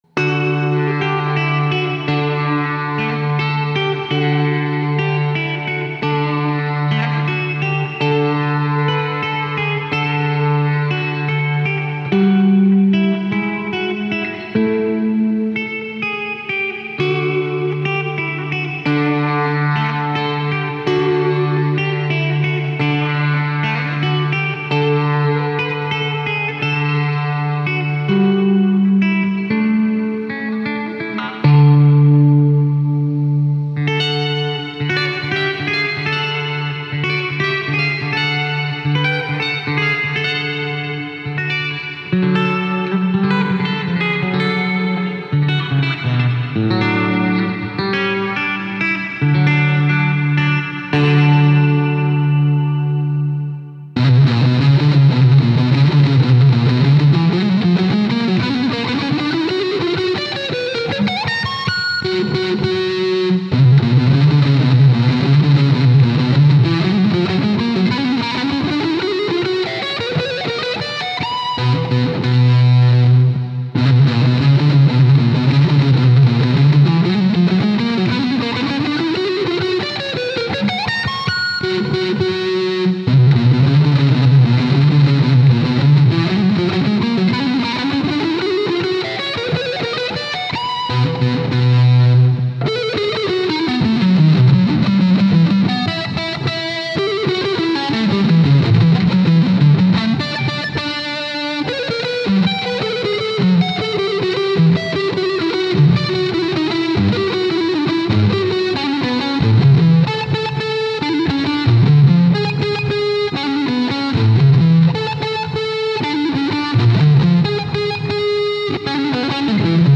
A TRANSCRIPTION FOR ELECTRIC GUITAR